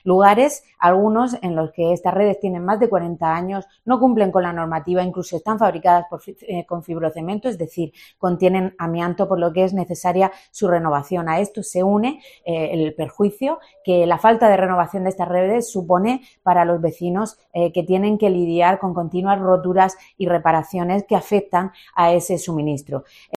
Isabel Casalduero, concejal del PSOE en Lorca